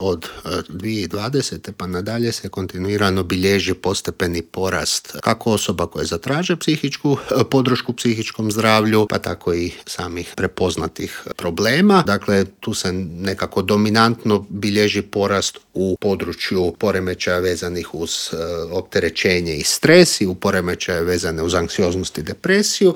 ZAGREB - Najdepresivniji je dan u godini pa smo u studiju Media servisa razgovarali o mentalnom zdravlju.